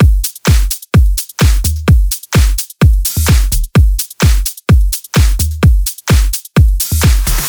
VDE 128BPM Dynamite Drums 1.wav